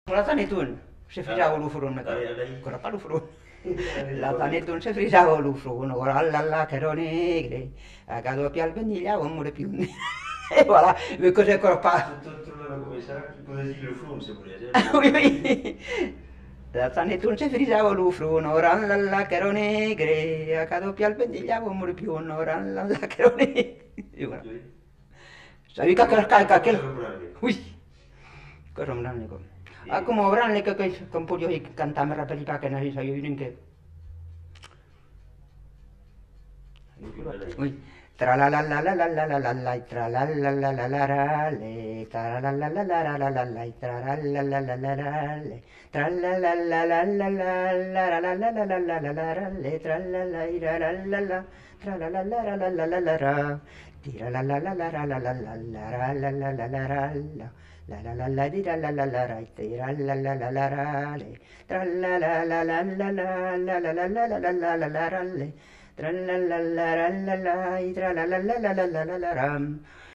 Lieu : Castillonnès
Genre : chant
Effectif : 1
Type de voix : voix de femme
Production du son : chanté ; fredonné
Danse : rondeau